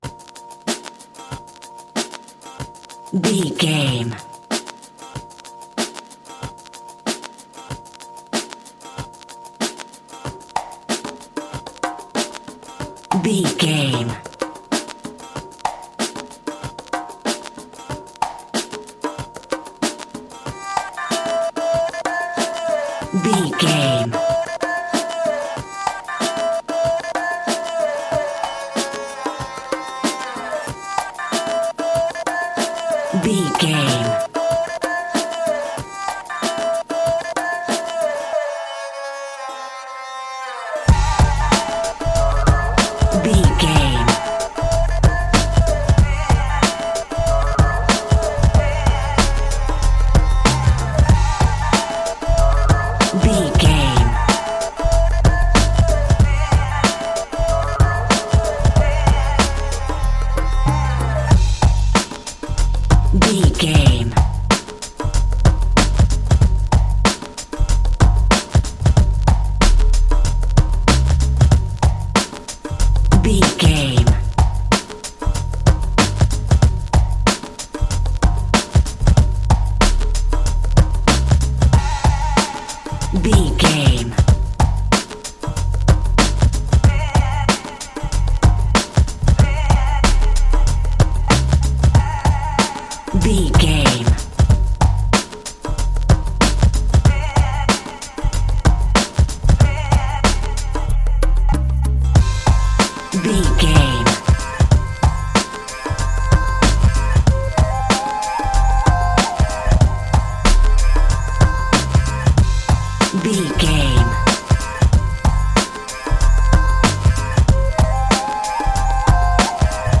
Aeolian/Minor
dreamy
mystical
ominous
percussion
conga
tabla